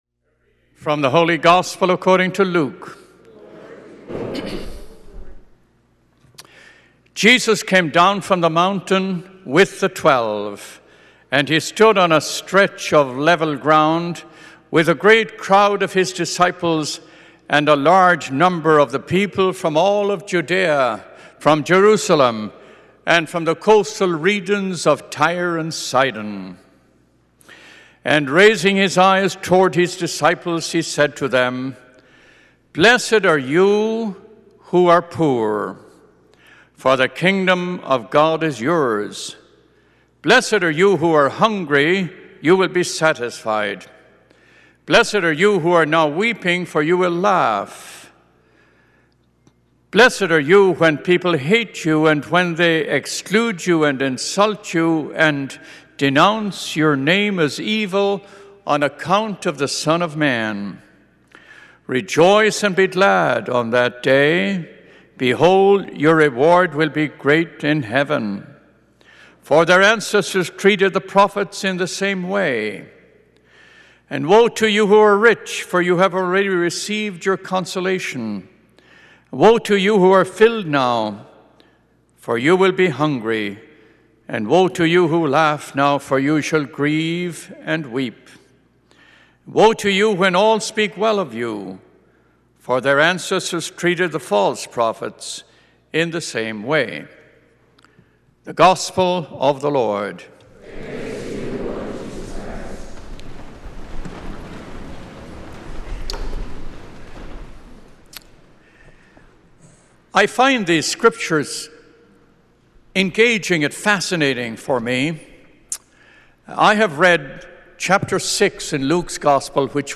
Gospel and Homily Podcasts
Holy Family Church Seventh Sunday in Ordinary Time, February 24, 2019, 11:15 Mass Play Episode Pause Episode Mute/Unmute Episode Rewind 10 Seconds 1x Fast Forward 10 seconds 00:00 / 18:27 Subscribe Share